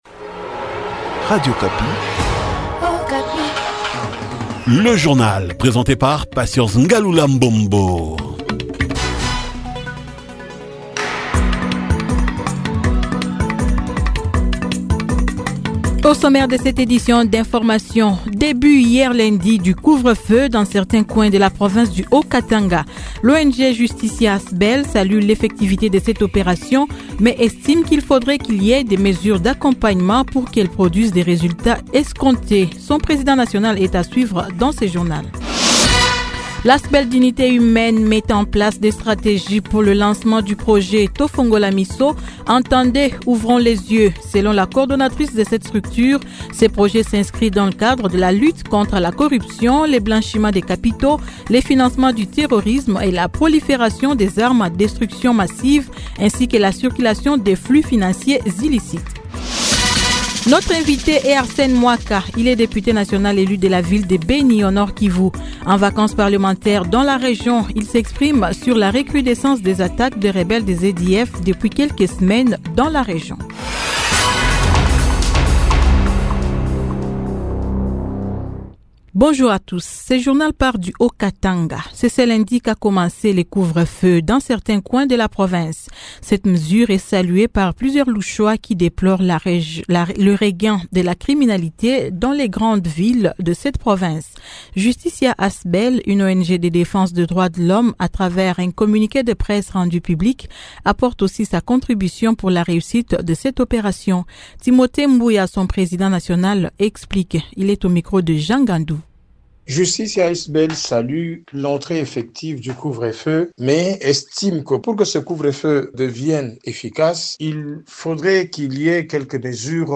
Journal matin 08h
Beni : invité Arsène Mwaka, député national élu de la ville de Beni.